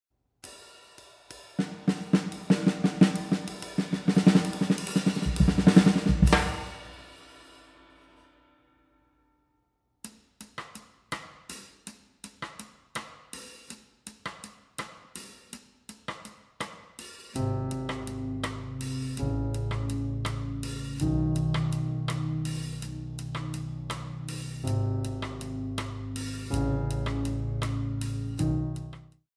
Yamaha Motif keyboard, Drums